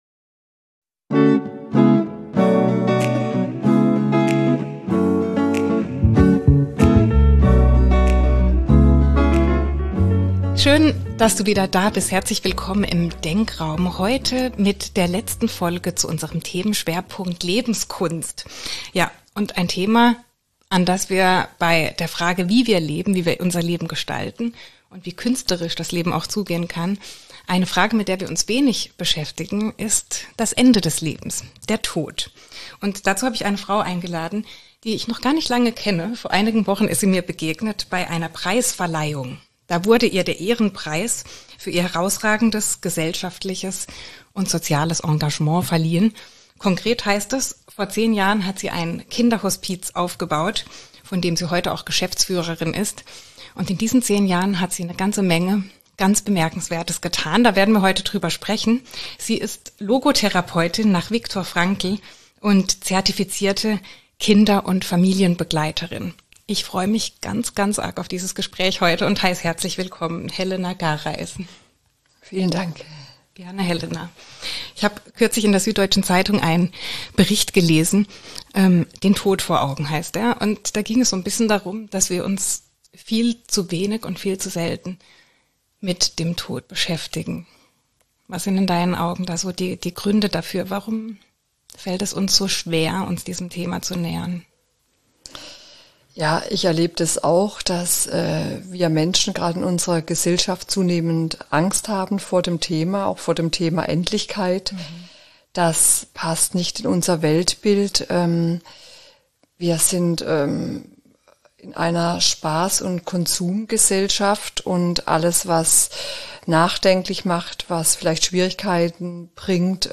Tabu-Thema Tod: Warum es wichtig ist, über das Sterben zu sprechen! Ein Gespräch über die Hospizarbeit